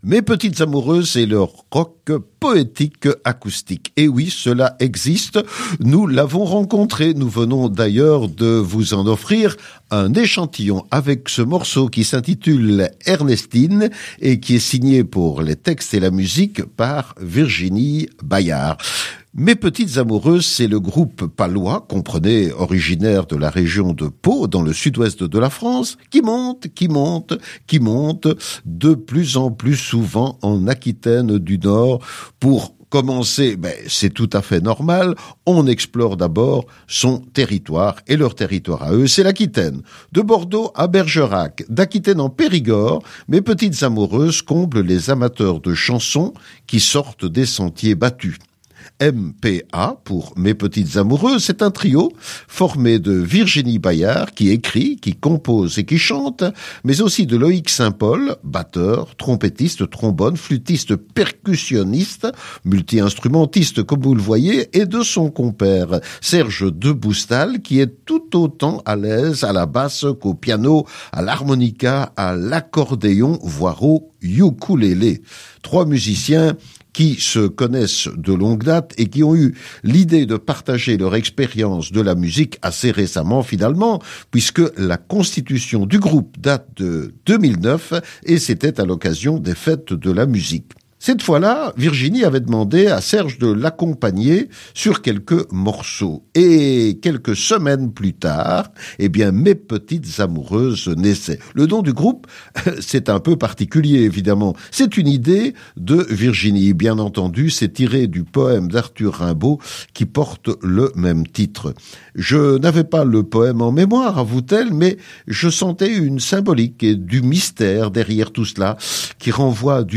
Composé de trois membres multi-instrumentistes